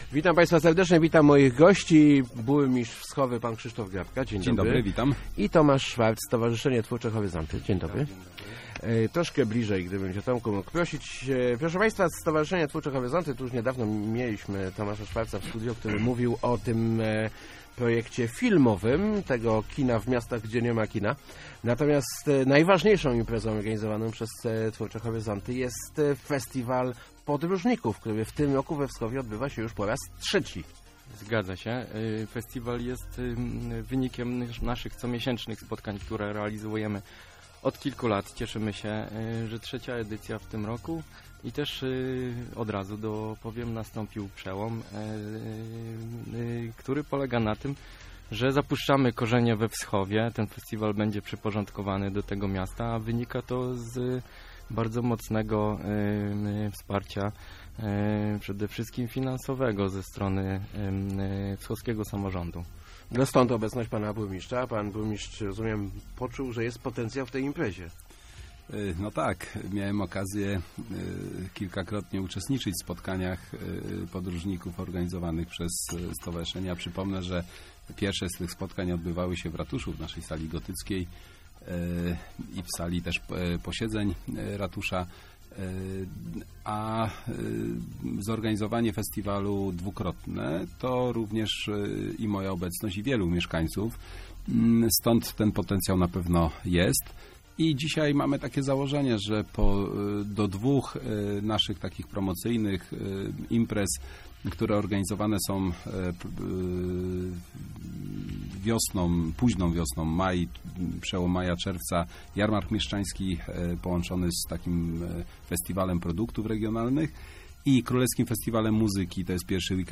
Kilkunastu podróżników z całej Polski zjedzie 31 stycznia do Wschowy na trzecią już edycję festiwalu "100droga". -To nasza trzecia sztandarowa impreza promująca miasto - mówił w Rozmowach Elki burmistrz Wschowy Krzysztof Grabka.